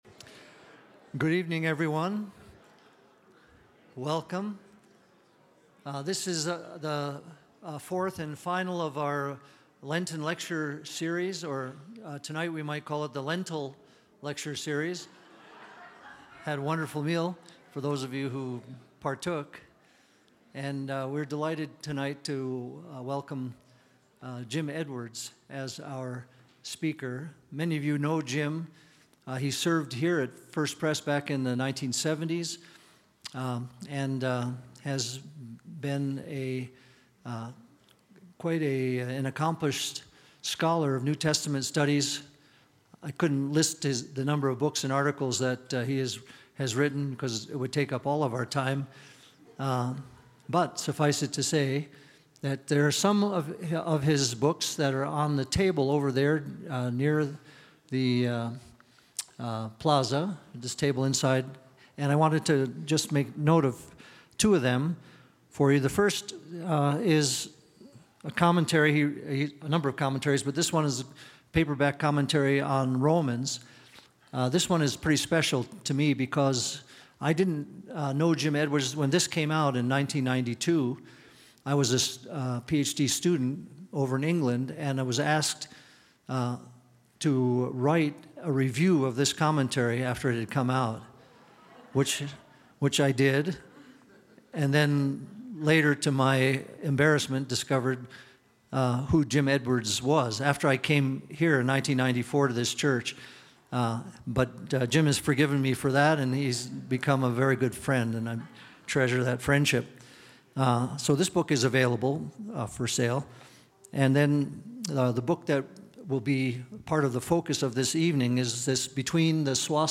2025 Lecture Series Audio What Do Humans Want?